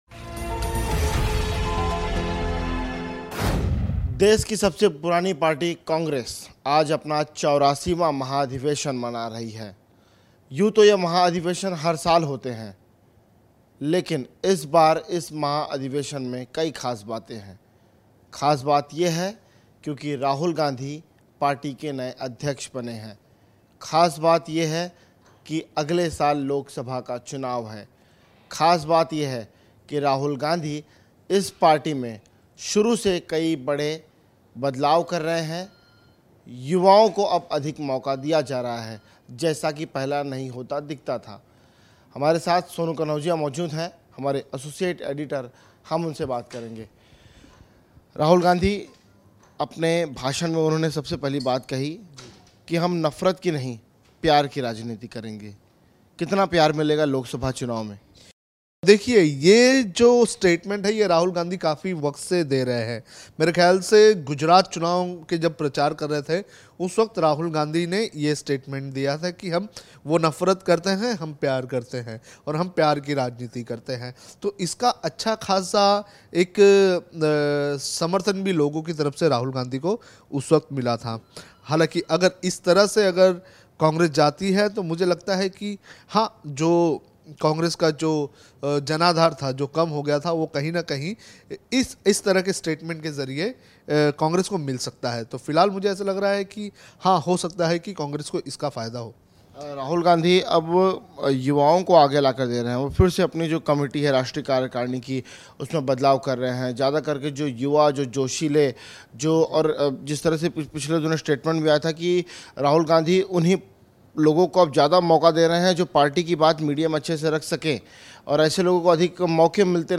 News Report / जानिए क्या कहा राहुल गांधी ने अधिवेशन में